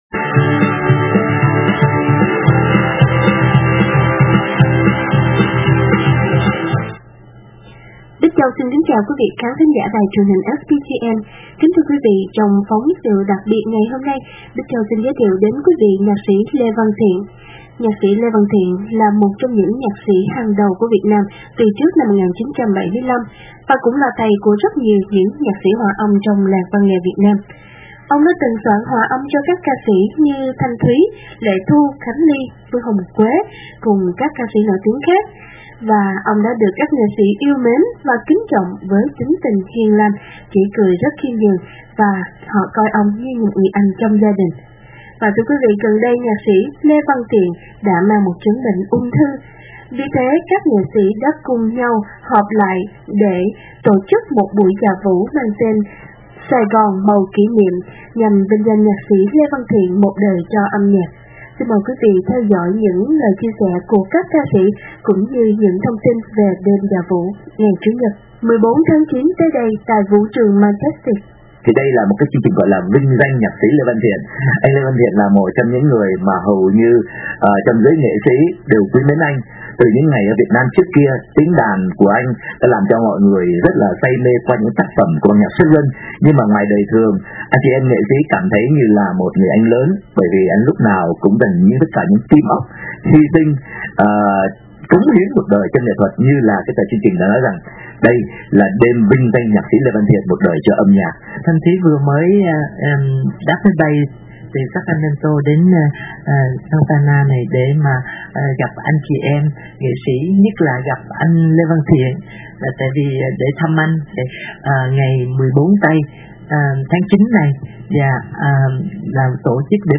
Phóng Sự Ðặc Biệt
Giọng nói của các Anh Chị Em Nghệ Sĩ, theo thứ tự xuất hiện:
3. Ca Sĩ Thanh Thúy
4. Ca Sĩ Lệ Thu
7. Ca Sĩ Phương Hồng Quế